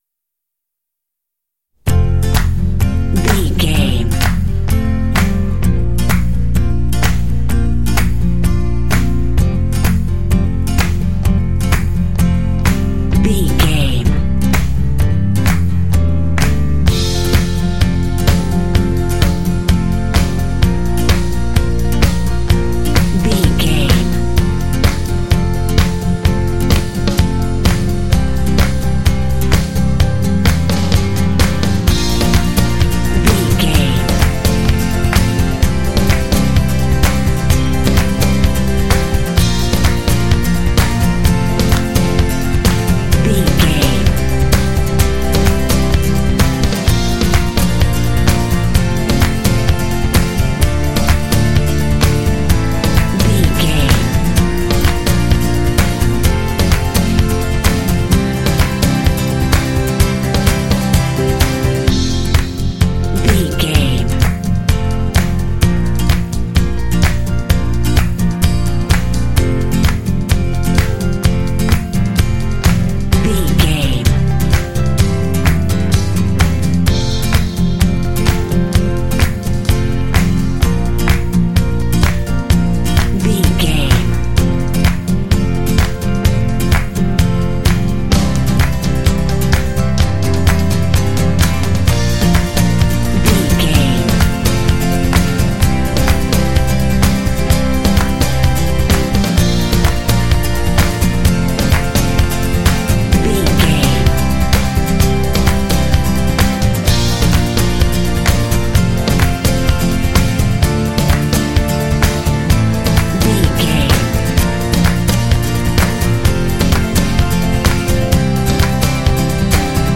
Uplifting
Ionian/Major
driving
acoustic guitar
piano
drums
percussion
bass guitar
strings
electric guitar
rock
stomp